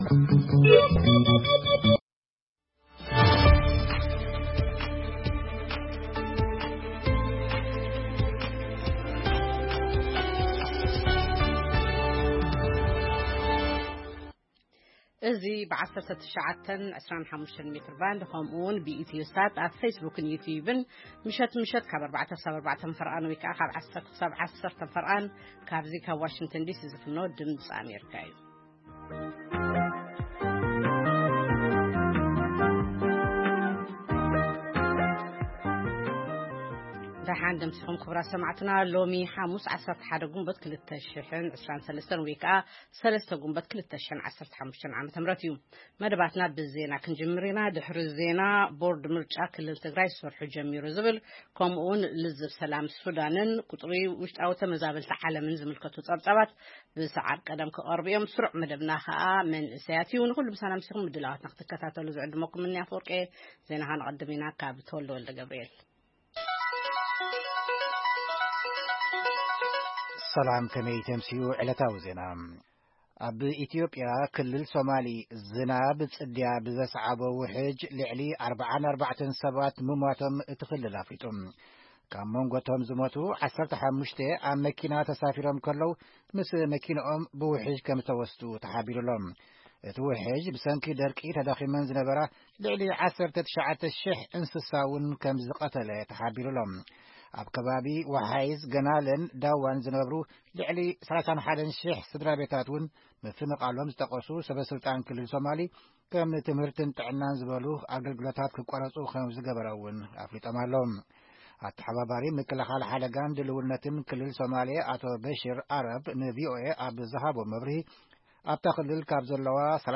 ፈነወ ድምጺ ኣመሪካ ቋንቋ ትግርኛ 11 ግንቦት 2023 ዜና (ኣብ ክልል ሶማል ኢትዮጵያ ዘጋጠመ ምዕልቕላቕ ዉሕጅ፡ ምብጻሕ ቦርድ ምርጫ ኢትዮጵያ ናብ ክልል ትግራይ፡ ኣመሪካ ኣብ ምቁራጽ ተኹሲ ሱዳን ዘለዋ ተስፋን ካል ኦትን) መደብ መንእሰያትን የጠቓልል።